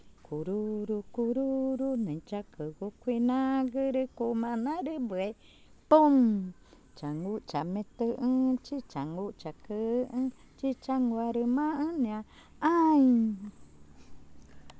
Cushillococha
Canción infantil